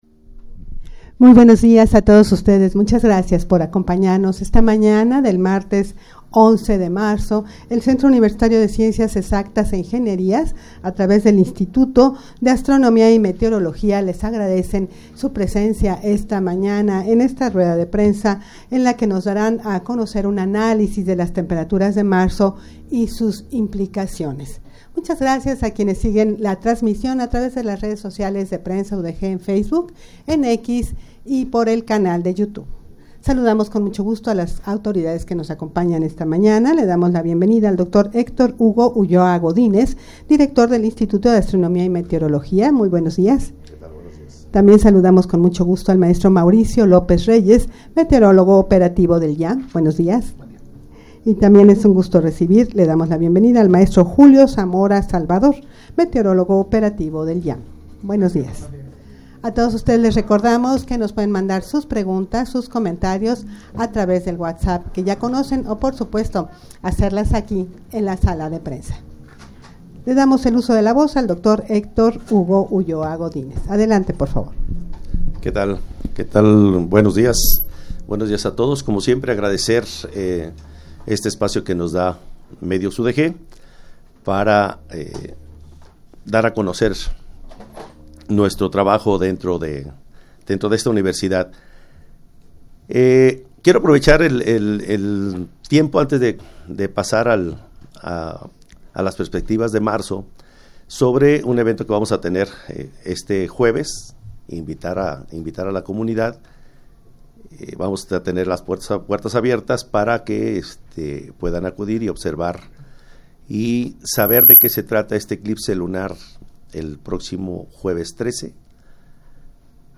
Audio de la Rueda de Prensa
rueda-de-prensa-las-temperaturas-de-marzo-y-sus-implicaciones.mp3